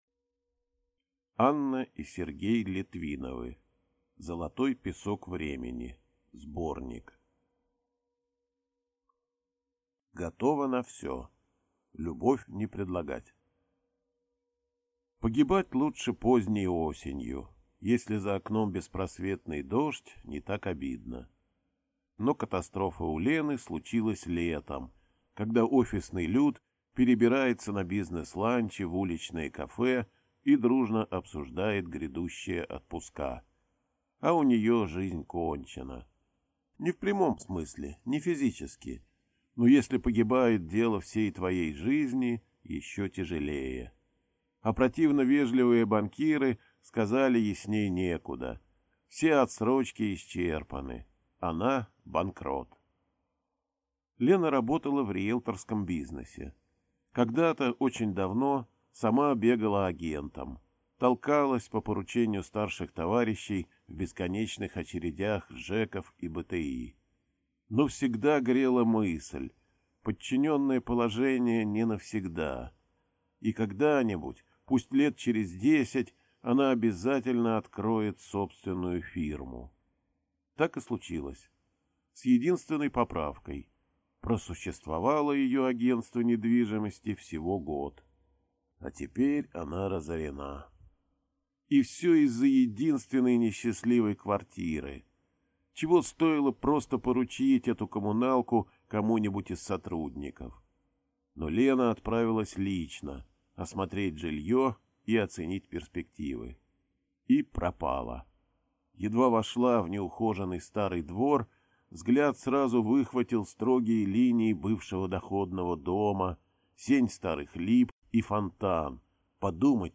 Аудиокнига Золотой песок времени (сборник) | Библиотека аудиокниг